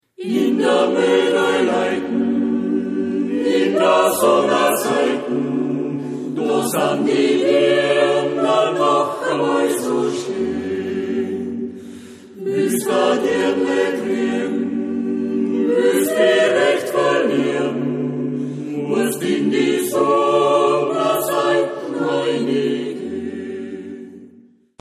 Musik aus dem Mostviertel
Aufgenommen im Tonstudio